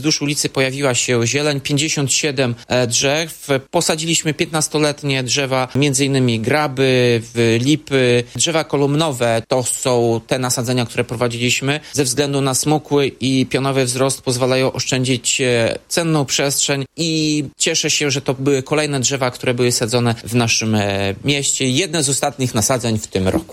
Mówił Tomasz Andrukiewicz, prezydent Ełku.